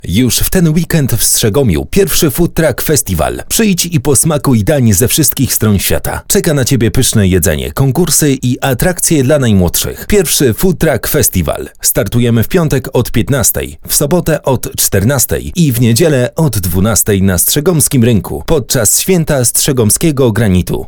Male 20-30 lat
A voice for dynamic productions.
Spot reklamowy